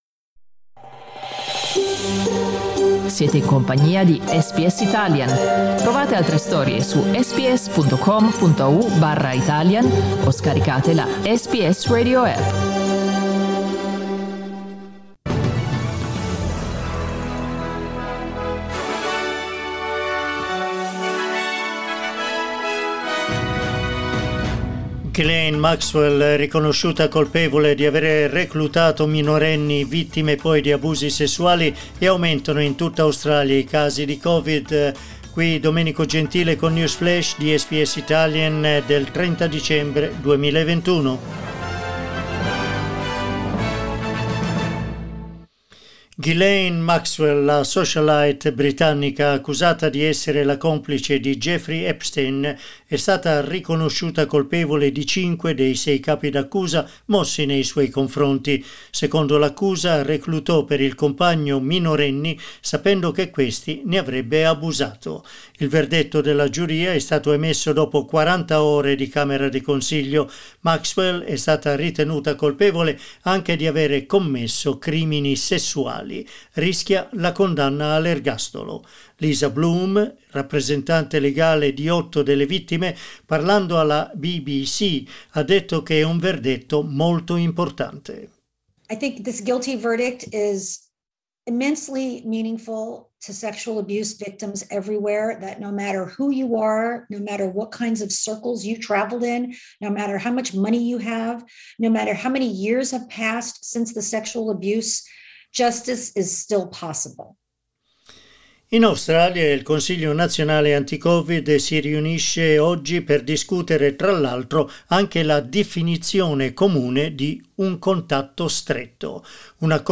News flash giovedì 30 dicembre 2021
L'aggiornamento delle notizie di SBS Italian.